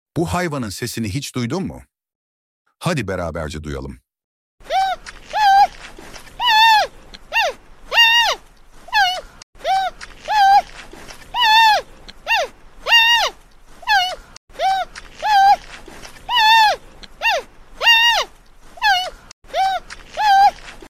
Panda Sesi Pandalar, dünyanın en sound effects free download